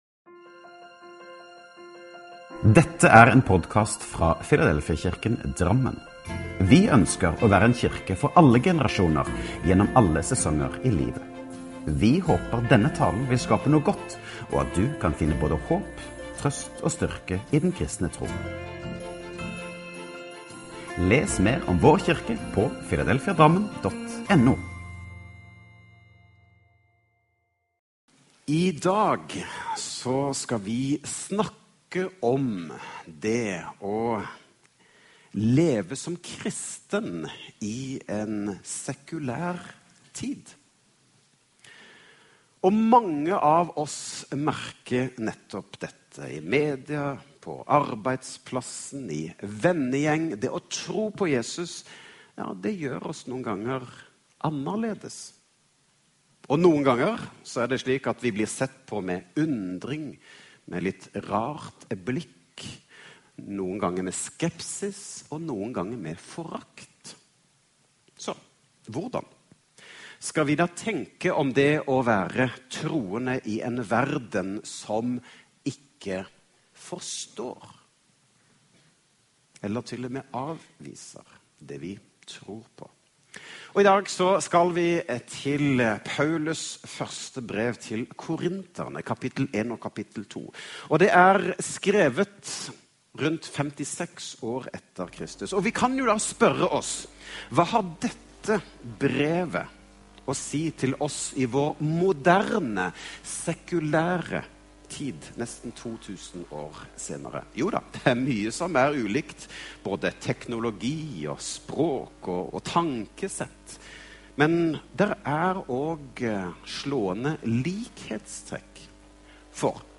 talen